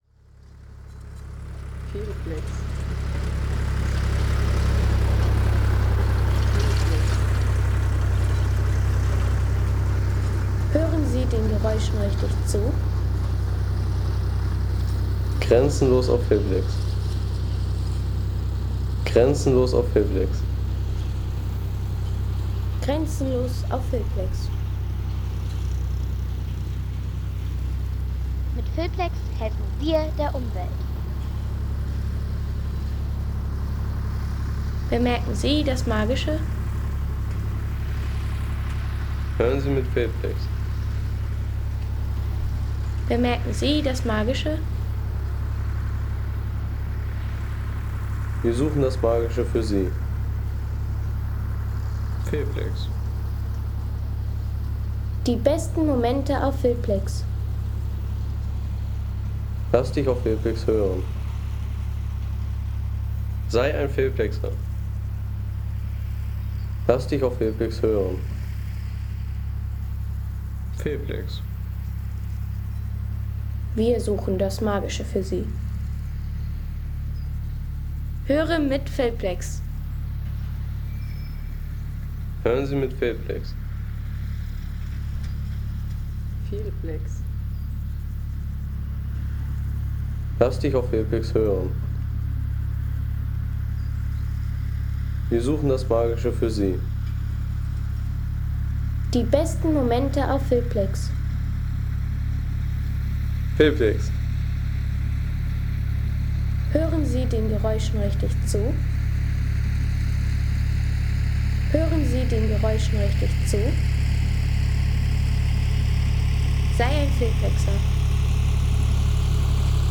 Traktor Kreiselheuer
Sommerliche Erntezeit – Der Klang frisch gemähten Heus.